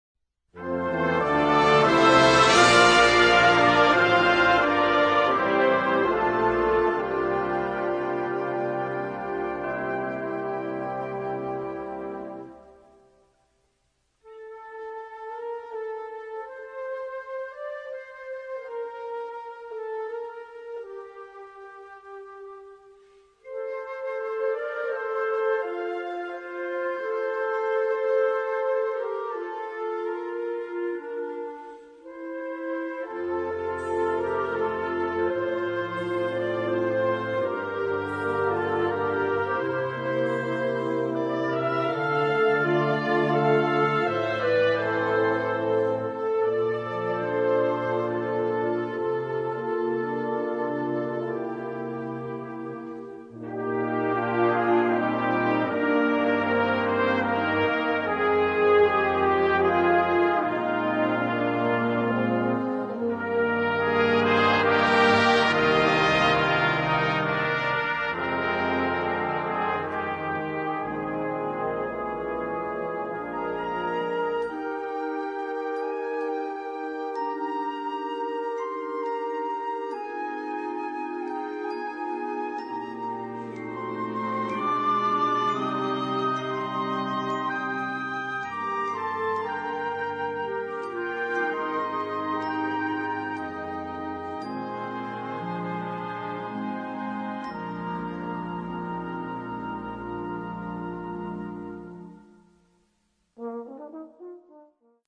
Noten für Blasorchester.